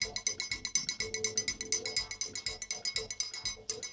Triangle.wav